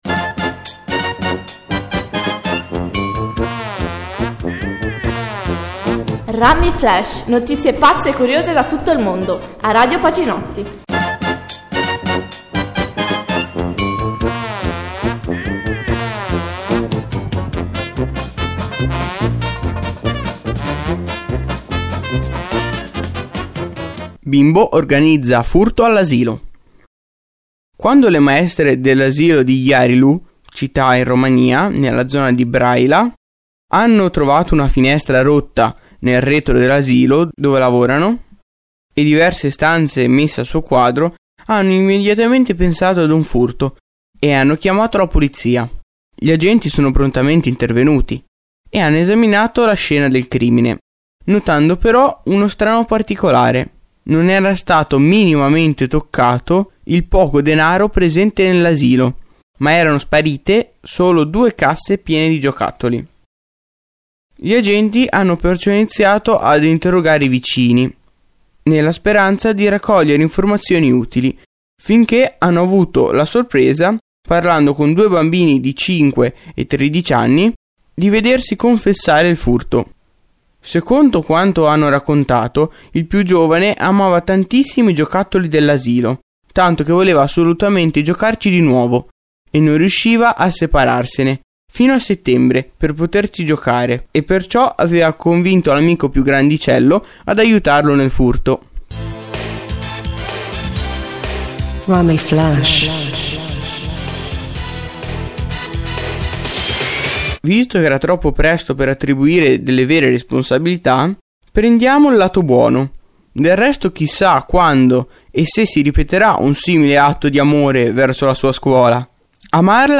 Radioweb Pacinotti Notizie curiose e brano di musica con relazione alla notizia